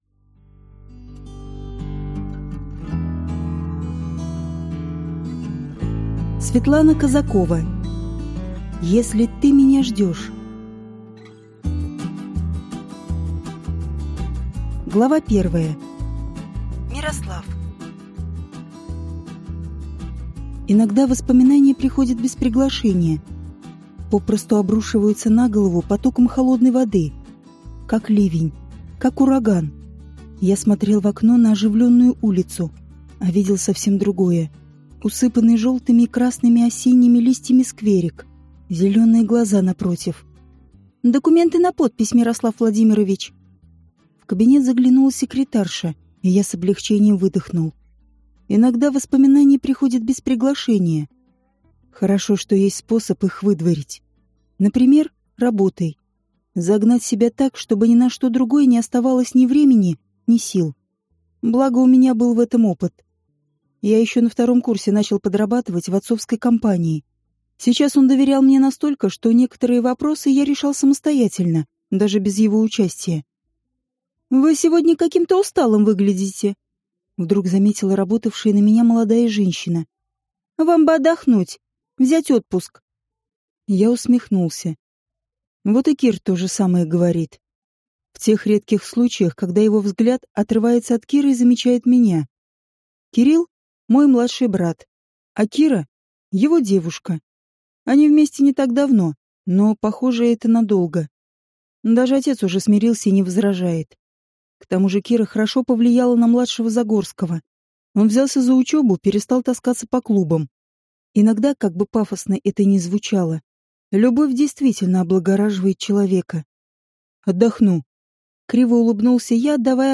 Аудиокнига Если ты меня ждёшь | Библиотека аудиокниг